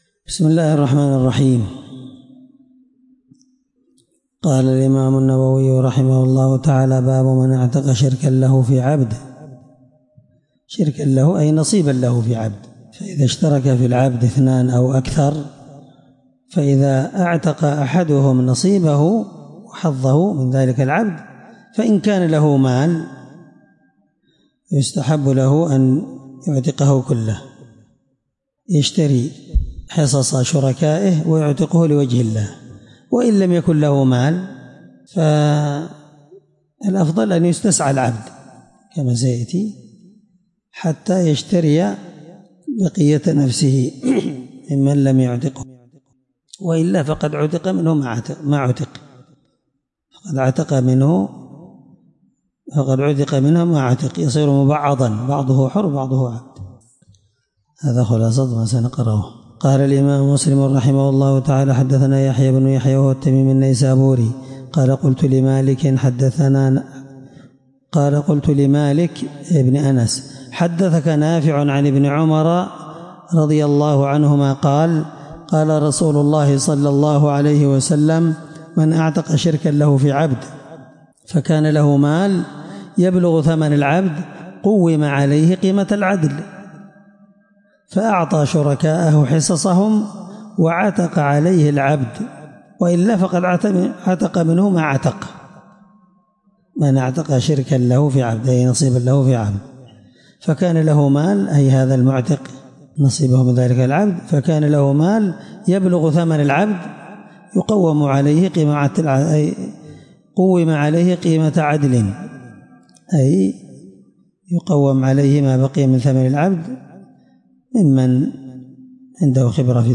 الدرس16من شرح كتاب الأيمان حديث رقم(1501-1503) من صحيح مسلم